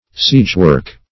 Meaning of siegework. siegework synonyms, pronunciation, spelling and more from Free Dictionary.
Search Result for " siegework" : The Collaborative International Dictionary of English v.0.48: Siegework \Siege"work`\, n. A temporary fort or parallel where siege guns are mounted.